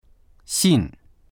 xìn 3 手紙
xin4.mp3